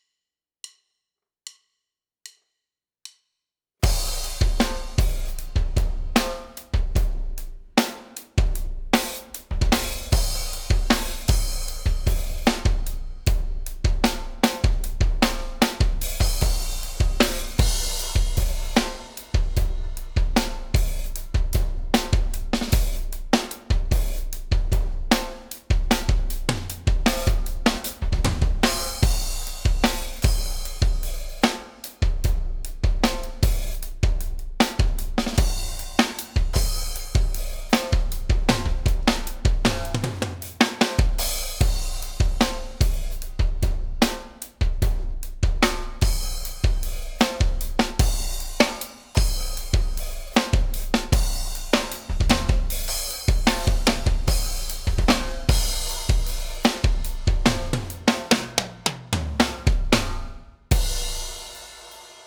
A = Analog Summing – via 16×2 – Passive Analog Summing Panel/Hardy M1 Preamp
A sounds a bit more harsh, more of those super fast transients coming through.
I prefer A because there’s more cohesion between frequencies.
The sound is a bit sterile but this can be easily fixed with the right amont of saturation while mastering.
a is more punchy and bright